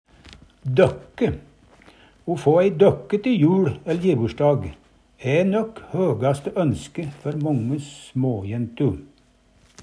døkke - Numedalsmål (en-US)